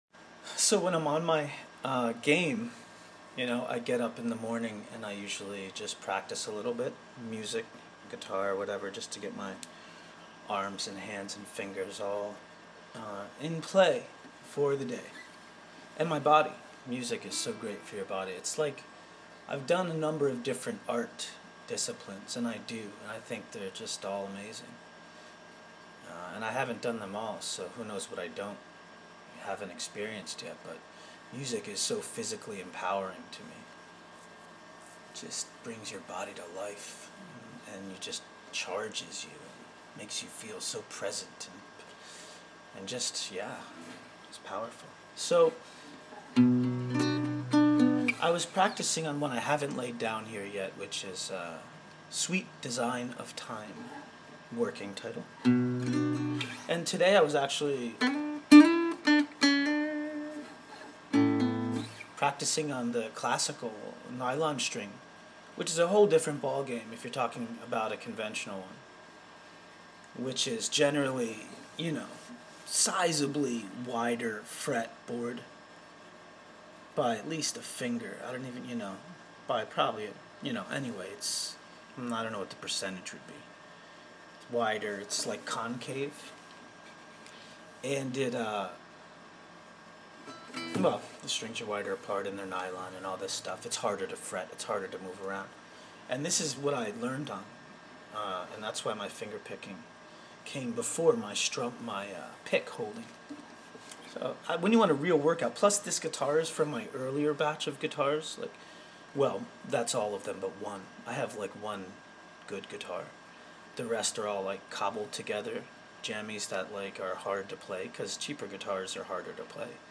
Dusting off the classical guitar because I think Sweet Design of Time may use classical over steel string. Talking about the difference between cheap guitars and quality ones.